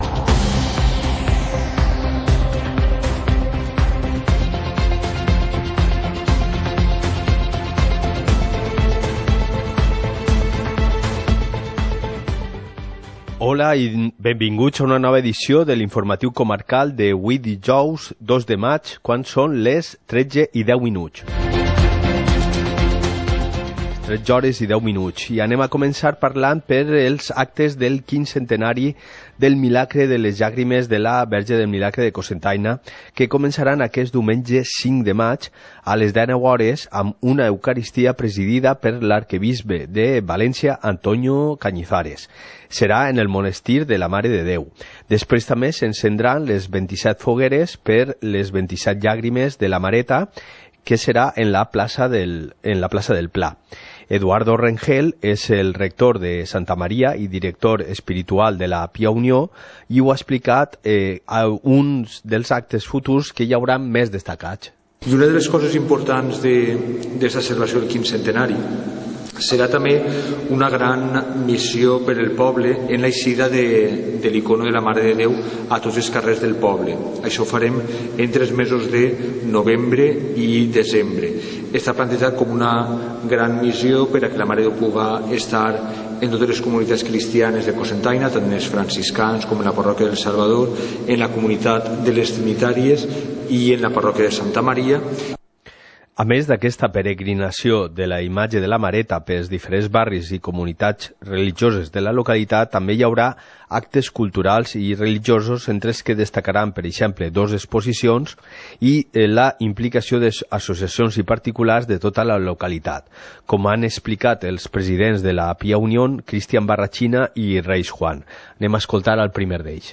Informativo comarcal - jueves, 02 de mayo de 2019